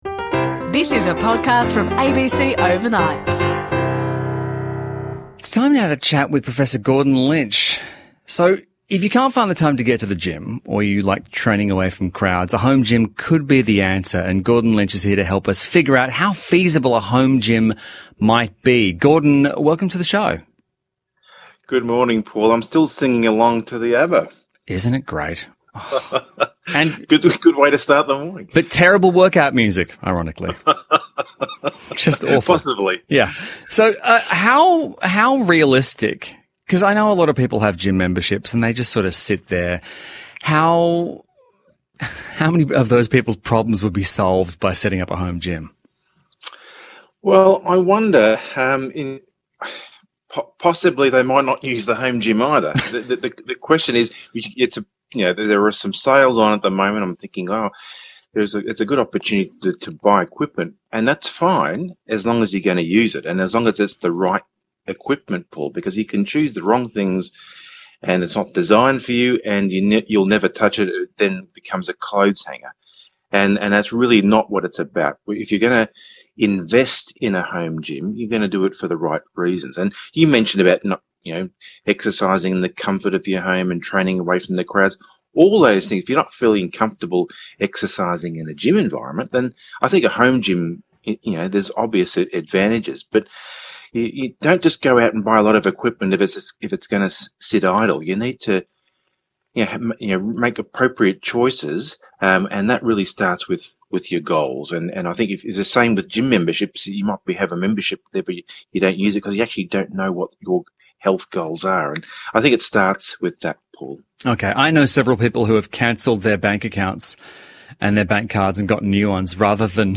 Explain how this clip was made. Overnights is heard from 2am to 6am nationally on the ABC.